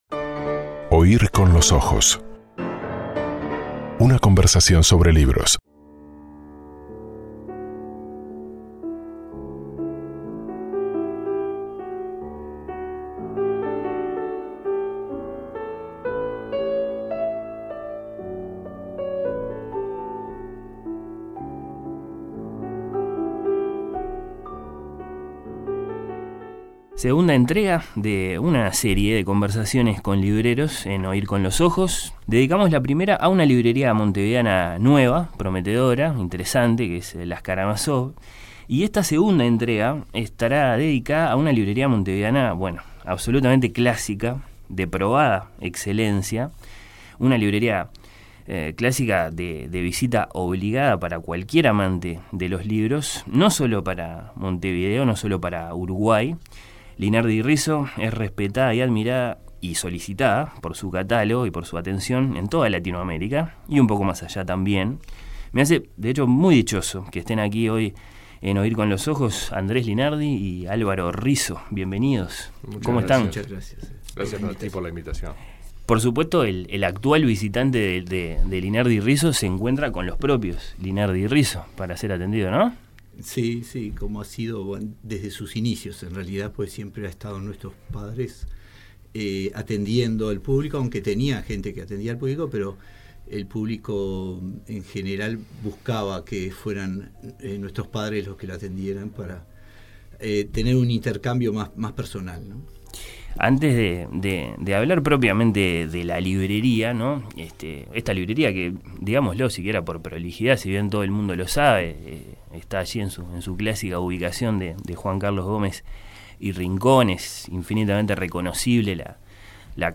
Conversaciones con libreros.